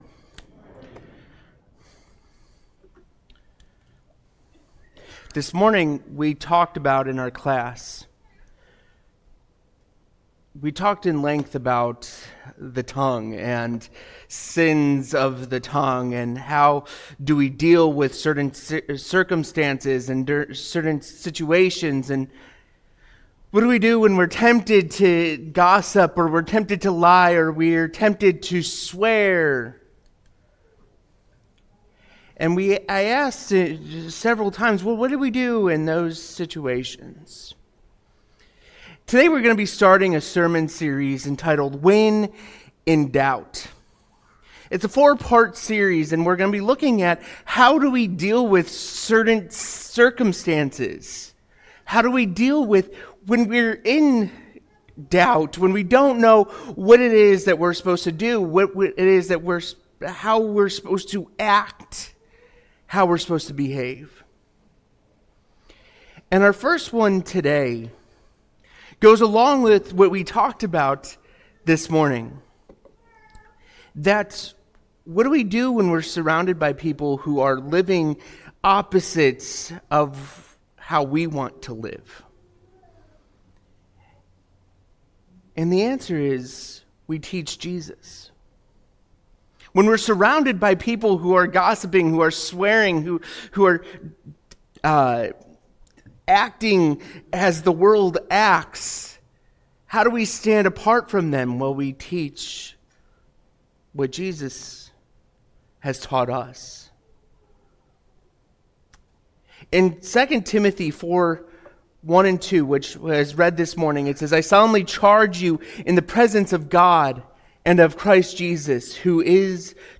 Sunday Sermons When In Doubt...